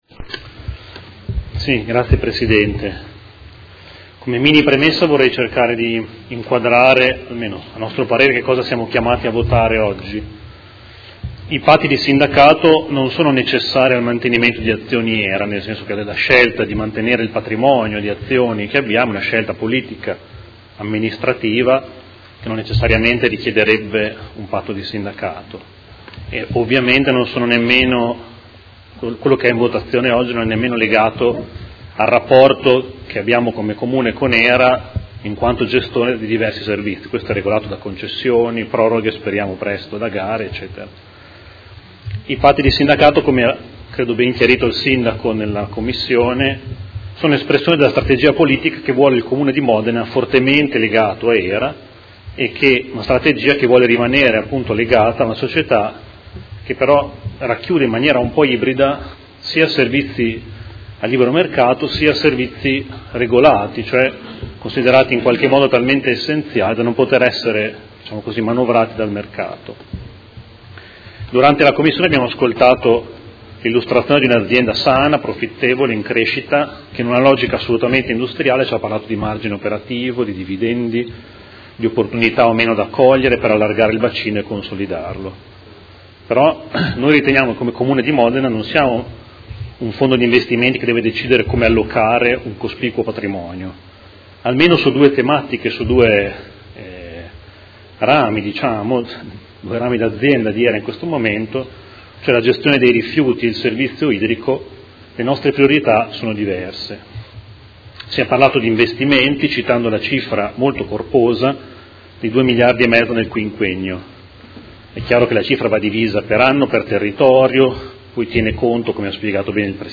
Seduta del 5/04/2018. Dibattito su proposta di deliberazione: Conclusione di Patti parasociali fra i Soci pubblici e fra i Soci pubblici di Area modenese di HERA S.p.A.